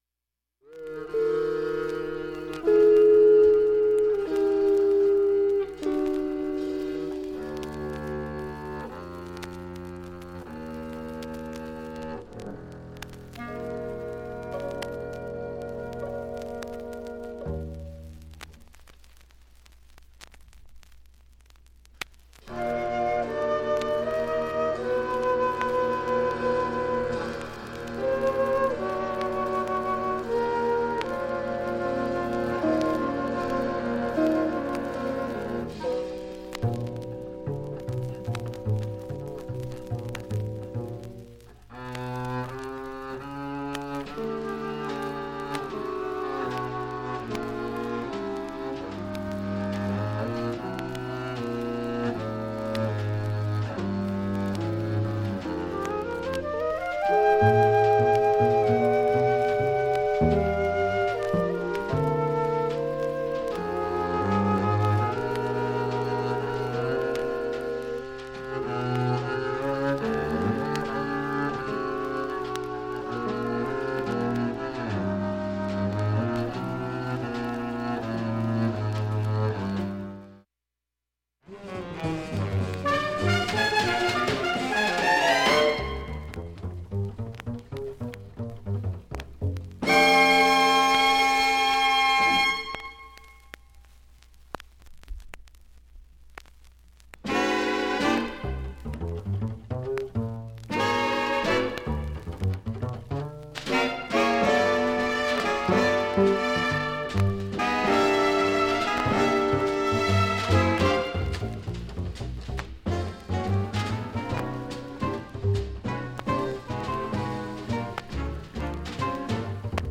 基本クリアないい音質です、
５回までのかすかなプツが２箇所
３回までのかすかなプツが８箇所
単発のかすかなプツが１０箇所